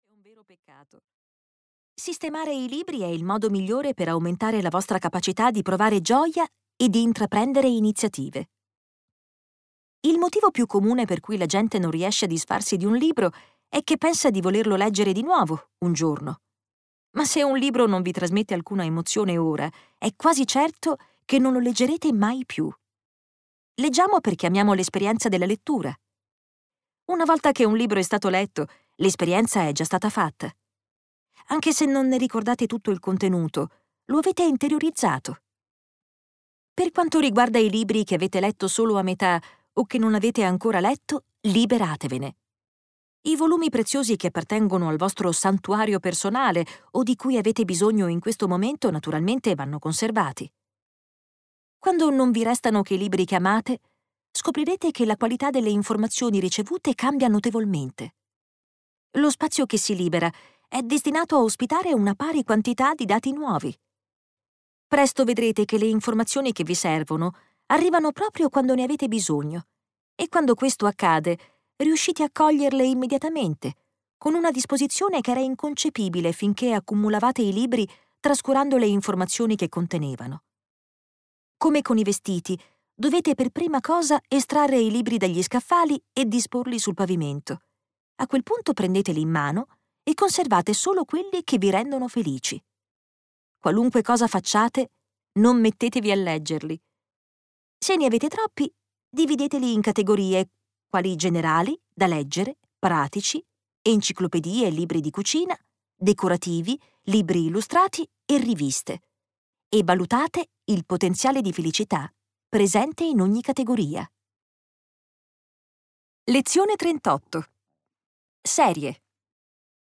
"96 lezioni di felicità" di Marie Kondo - Audiolibro digitale - AUDIOLIBRI LIQUIDI - Il Libraio